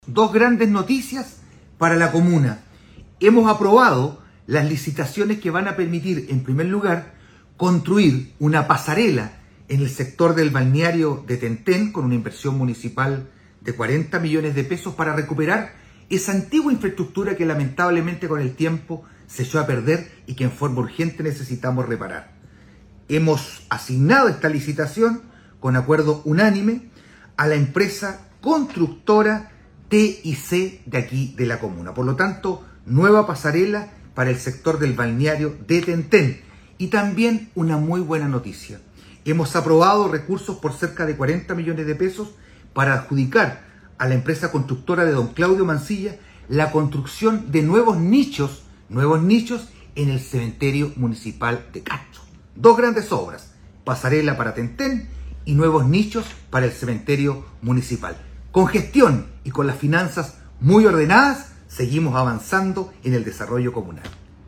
El anuncio lo hizo el alcalde Juan Eduardo Vera quien valoró la disposición de los integrantes del Concejo al aprobar los recursos necesarios y cuyas adjudicaciones ya se hicieron a dos empresas de la comuna quienes deberán comenzar los trabajos dentro de los próximos días.
ALCALDE-VERA-PROYECTOS.mp3